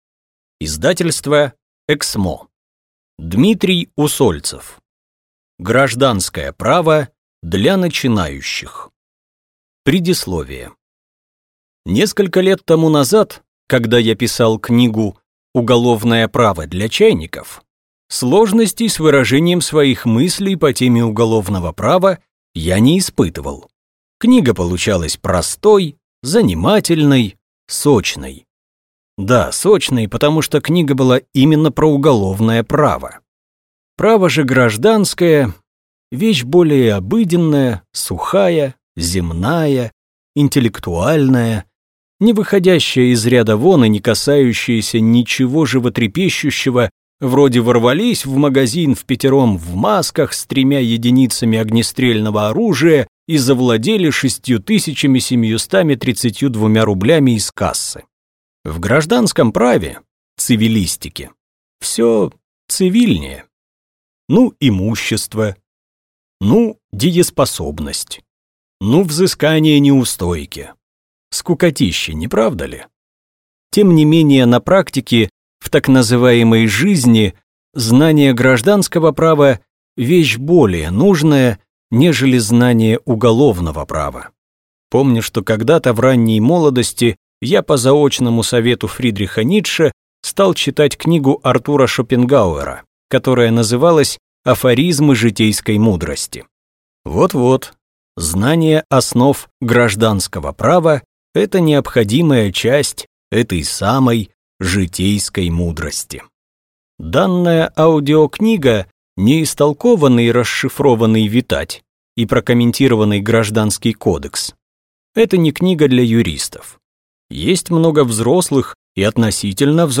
Аудиокнига Гражданское право для начинающих | Библиотека аудиокниг